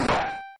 Bonk 2